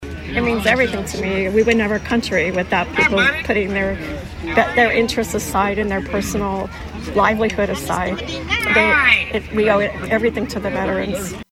Students from Southeast of Saline joined Cornerstone’s student body and a large crowd on hand to honor all veterans at the Salina / Saline County War Memorial in Sunset Park.